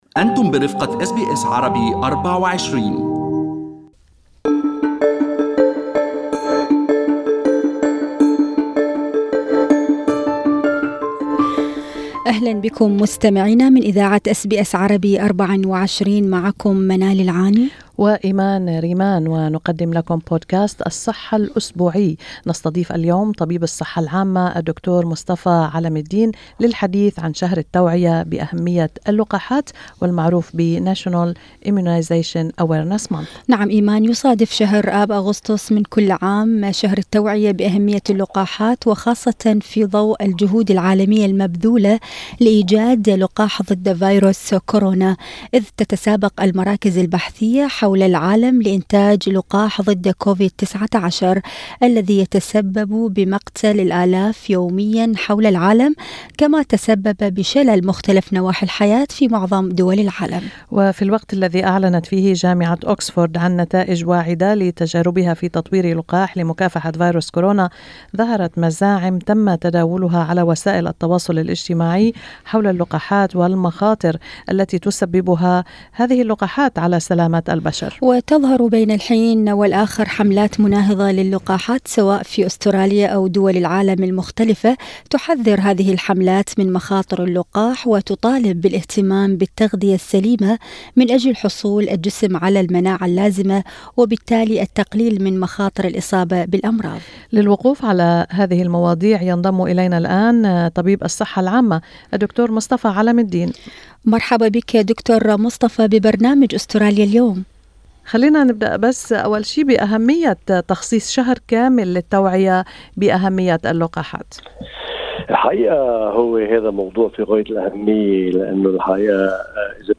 المزيد في التدوين الصوتي اعلاه مع طبيب الصحة العامة